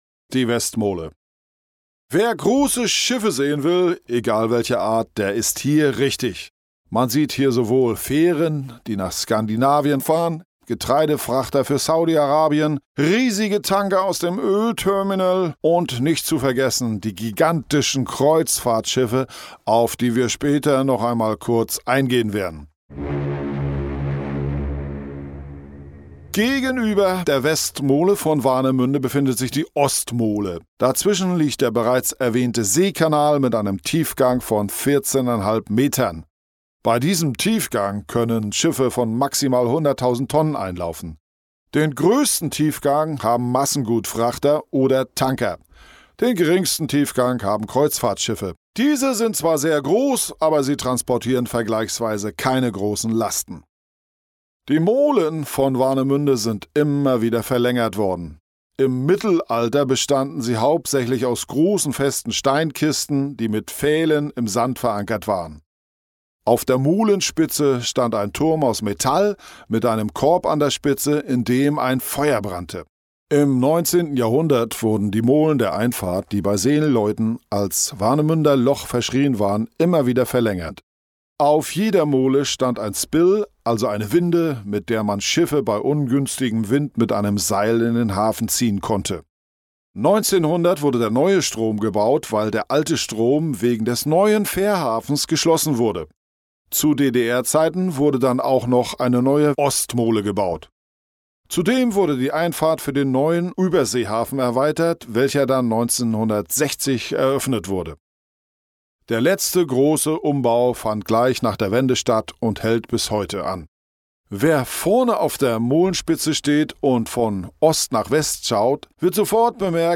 Audioguide
Audioguide Warnemünde - Station 7: Westmole